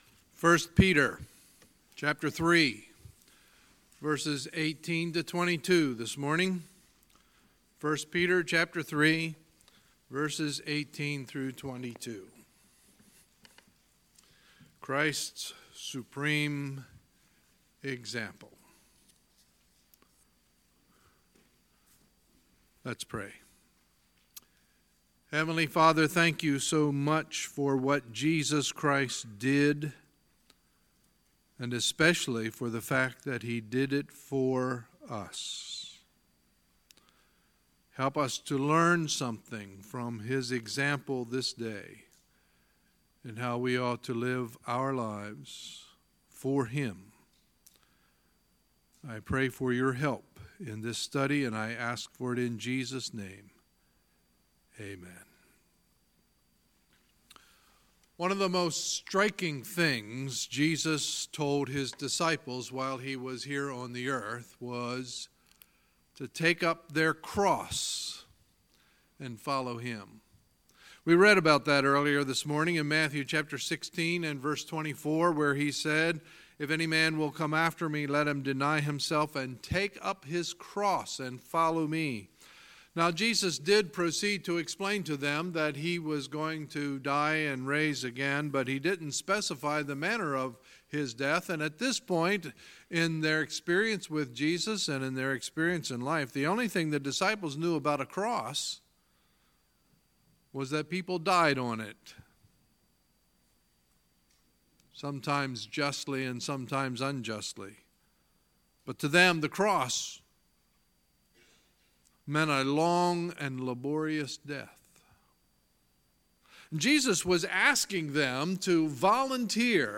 Sunday, June 3, 2018 – Sunday Morning Service